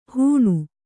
♪ hūṇu